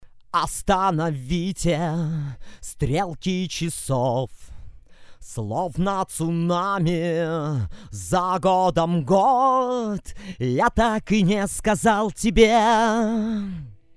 то ли вокалист такой, то ли несколько глуховатенько звучит.
Вложения Studio session.mp3 Studio session.mp3 545,5 KB · Просмотры: 1.843